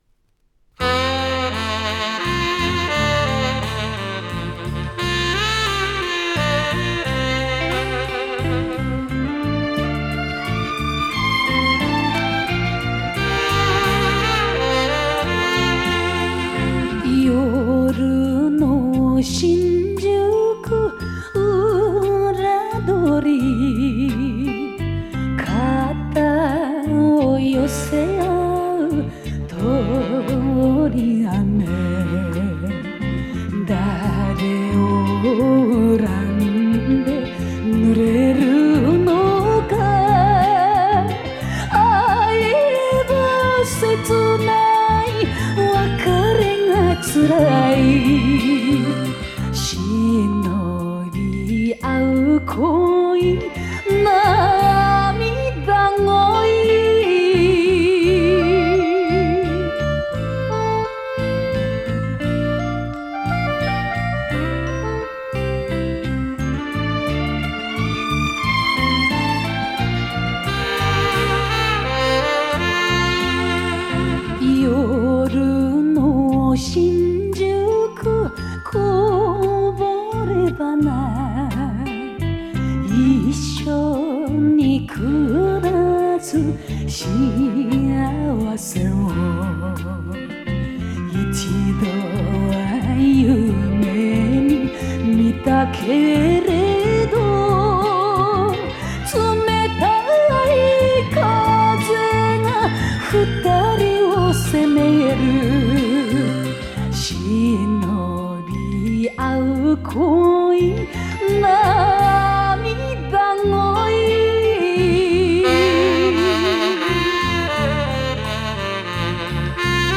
picЖанр: Enka